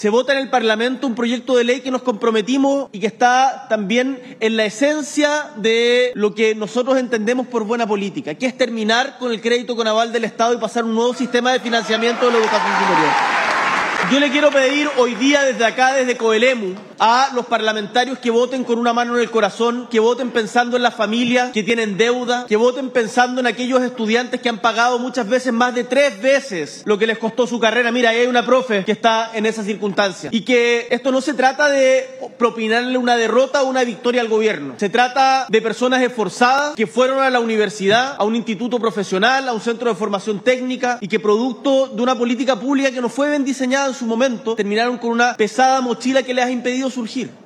Desde Coelemu, en la Región de Ñuble, el Mandatario subrayó que la propuesta no debe verse como una victoria o derrota política, sino como un acto de justicia hacia las familias endeudadas.